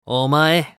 男性
熱血系ボイス～日常ボイス～
【名前を呼ぶ1（普通）】